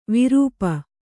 ♪ virūpa